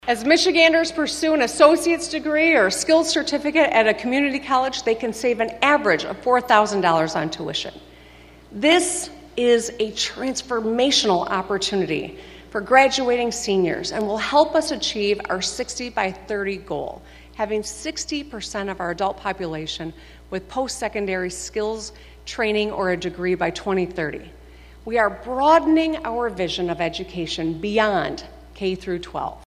AUDIO: Governor Whitmer delivers State of the State
She called for that in her 2024 State of the State address before a joint session of the House and Senate.